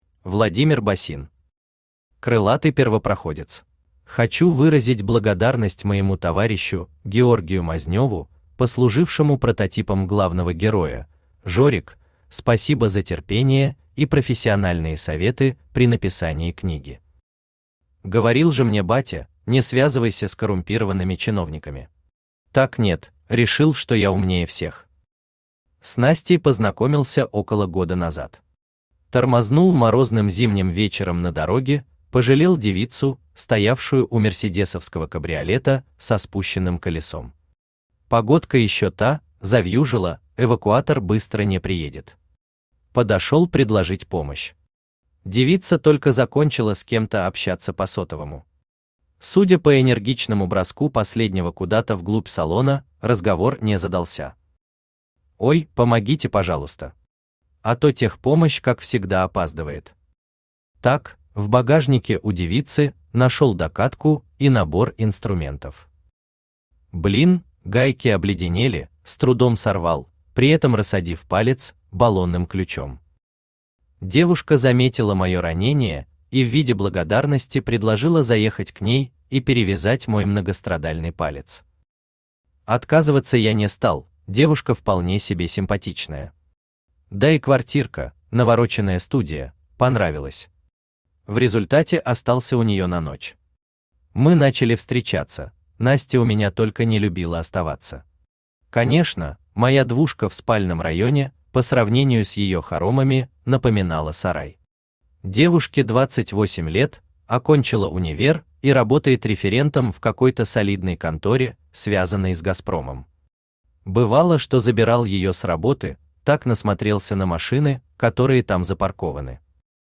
Аудиокнига Крылатый первопроходец | Библиотека аудиокниг
Aудиокнига Крылатый первопроходец Автор Владимир Георгиевич Босин Читает аудиокнигу Авточтец ЛитРес.